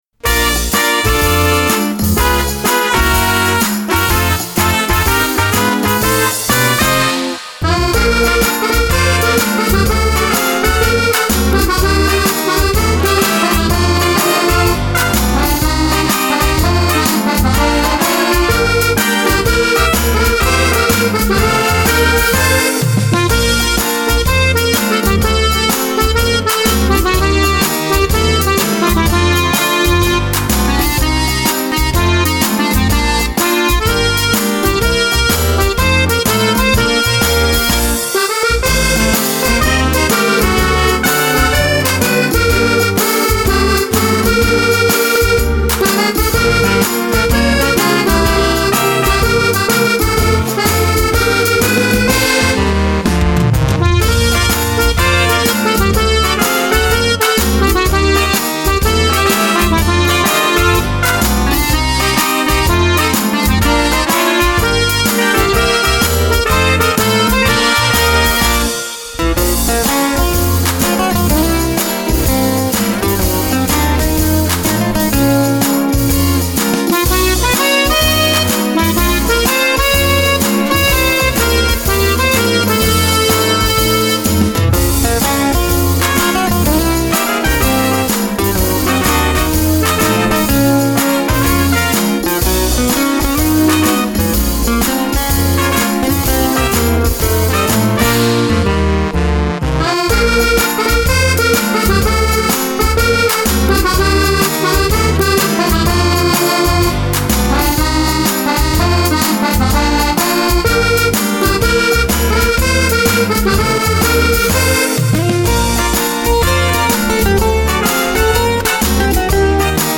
version accordéon intégrale